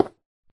Sound / Minecraft / dig / stone4.ogg
stone4.ogg